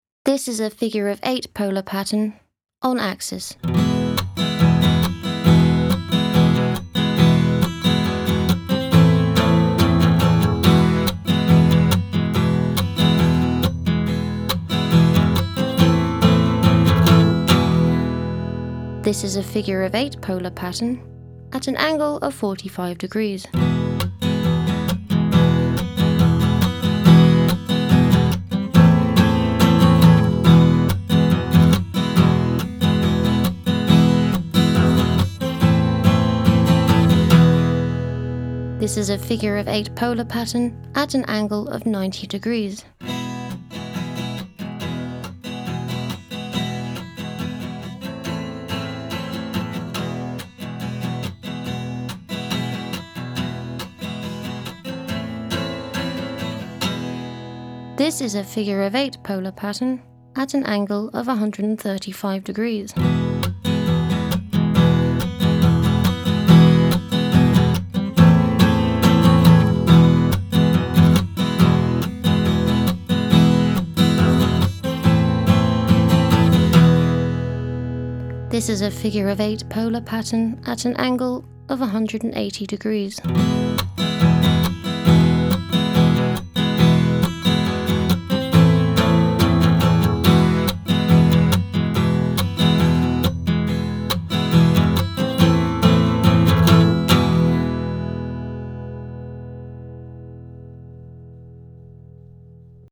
Figure en 8:
Les micros bidirectionnels ou figure en 8 (directivité 5 sur l’image) captent aussi bien le son en provenance de l’avant que celui en provenance de l’arrière. Ils sont insensibles aux sons qui proviennent des côtés (90 degrés).